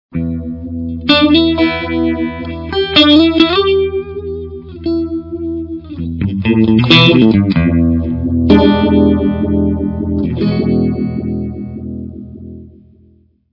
machine gun
Phaser
phaser01.wav